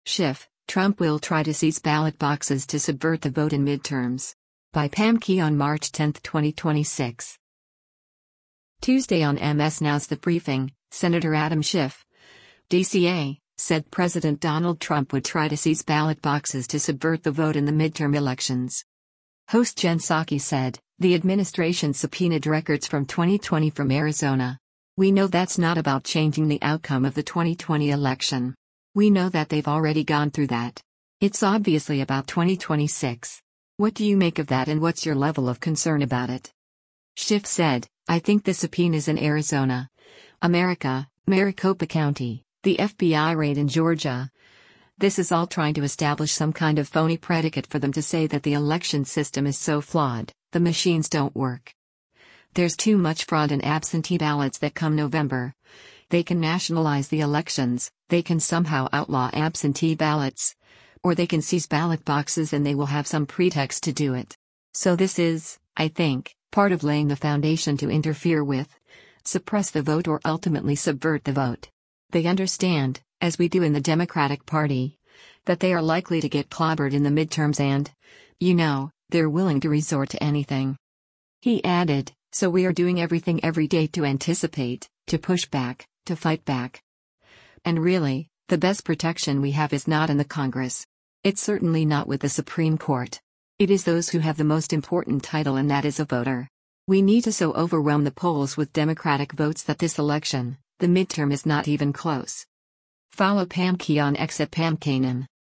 Tuesday on MS NOW’s “The Briefing,” Sen. Adam Schiff (D-CA) said President Donald Trump would try to “seize ballot boxes’ to “subvert the vote” in the midterm elections.